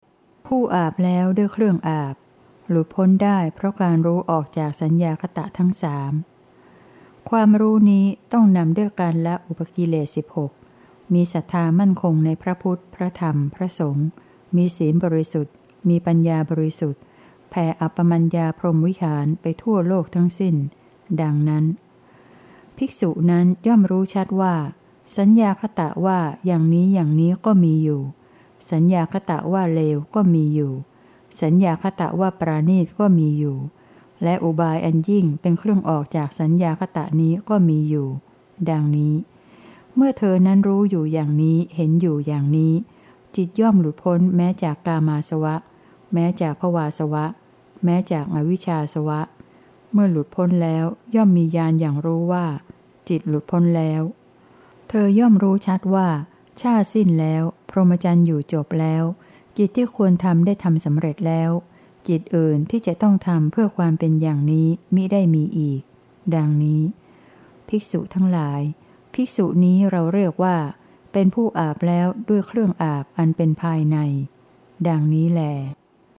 เสียงอ่าน